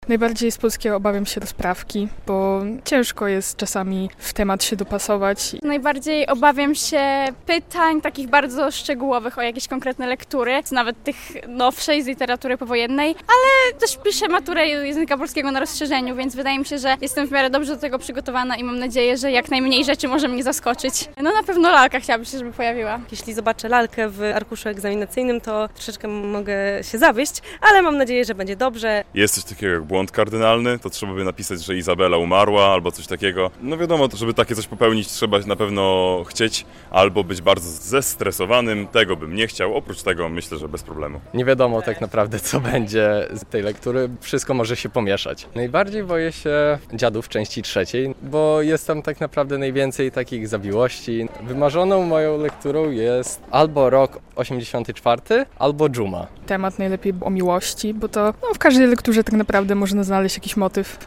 Białostoccy maturzyści przed egzaminem z języka polskiego - relacja